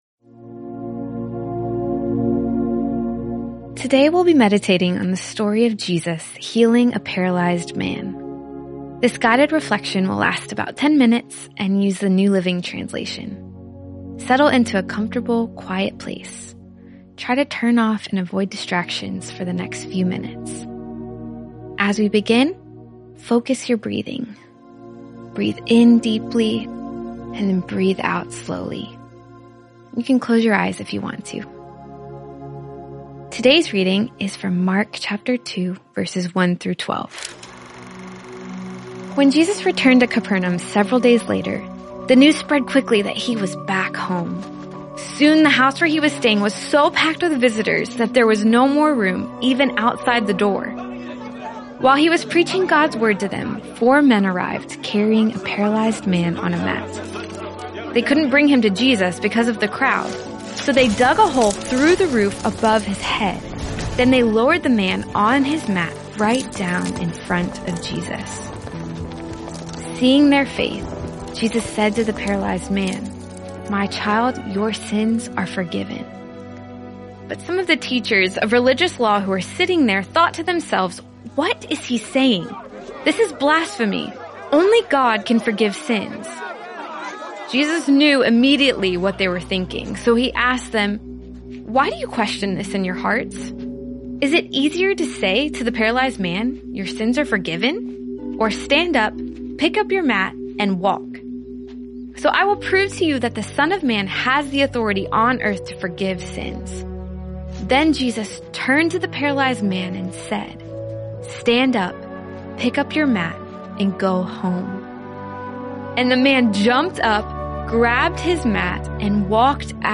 Today we’ll be meditating on the story of Jesus healing a paralyzed man. This guided reflection will last about ten minutes and uses the New Living Translation.